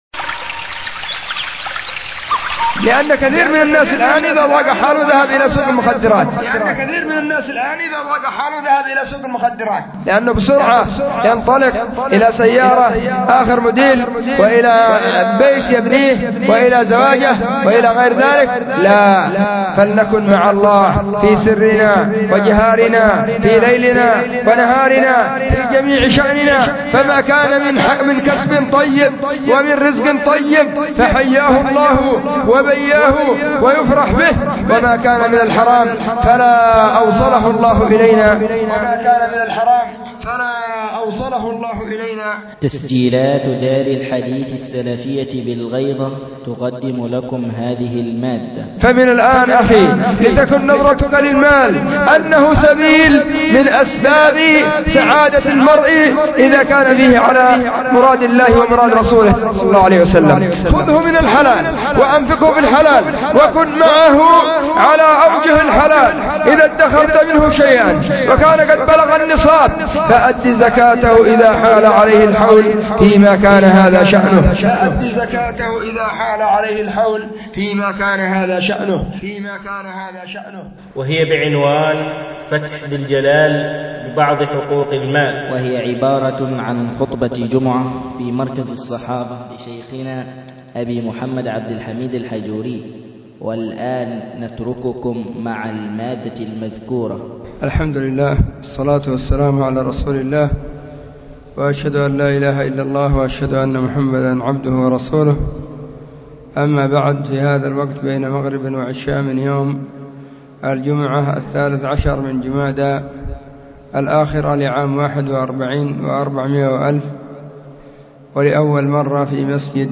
محاضرة متممة لخطبة الجمعة بعنوان فتح ذي الجلال ببعض حقوق المال.
محاضرة_تتمه_الخطبة_بعنوان_فتح_ذي.mp3